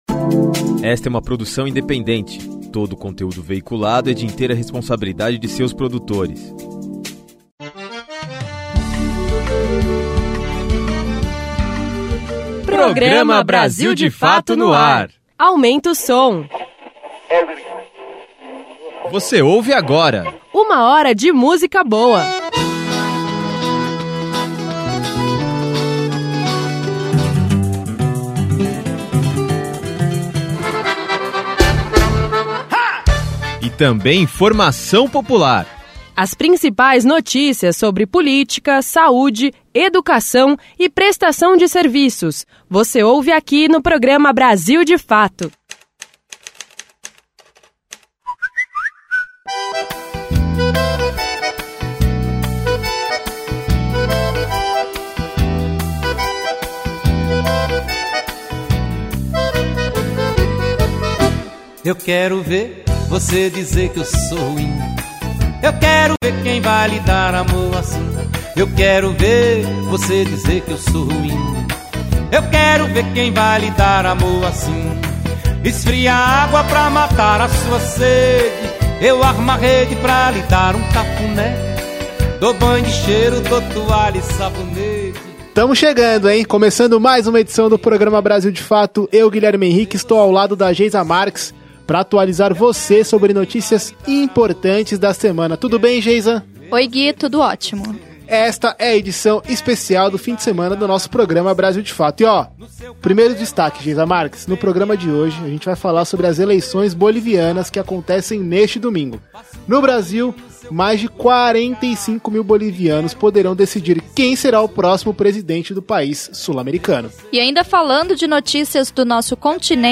Rádio
Você confere também uma reportagem que fala sobre a vitória do povo equatoriano, que foi às ruas e conseguiu barrar o aumento no preço dos combustíveis.